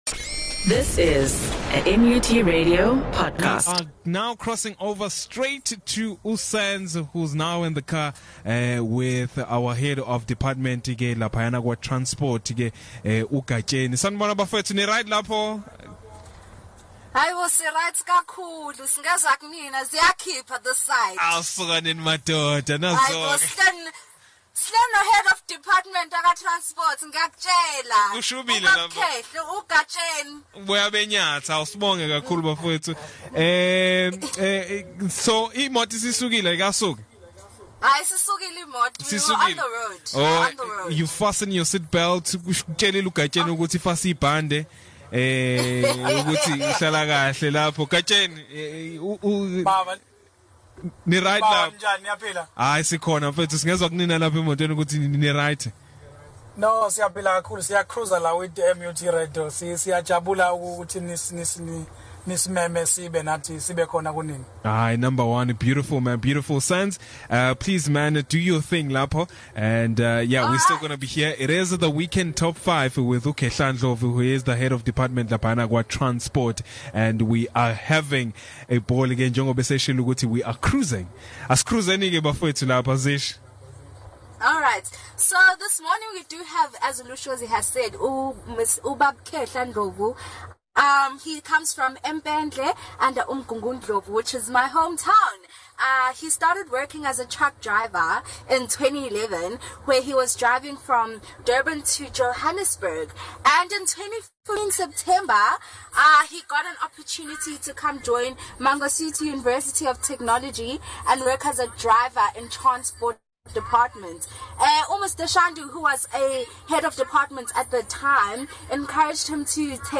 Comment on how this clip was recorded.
The Interview was done in a car while driving around the campus.